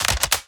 Added more sound effects.
GUNMech_Insert Clip_01_SFRMS_SCIWPNS.wav